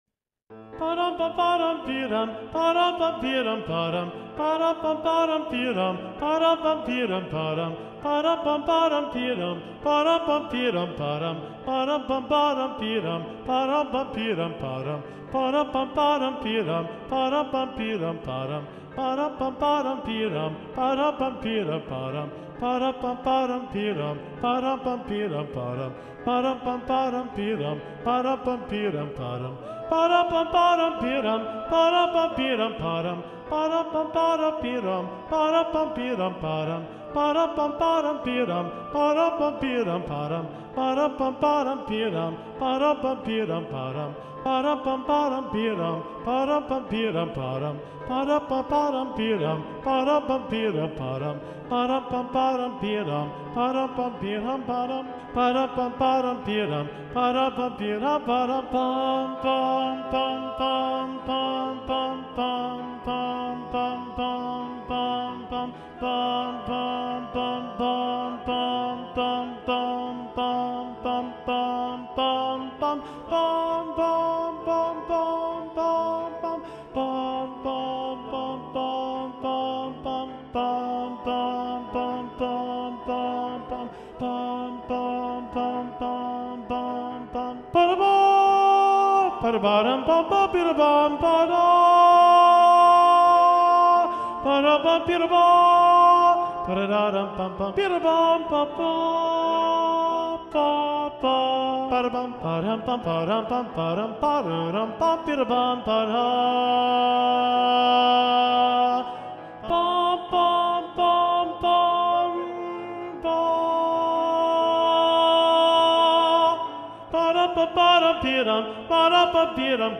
- Œuvre pour chœur à 7 voix mixtes (SSAATTB) + piano
SATB Tenor 1 (chanté)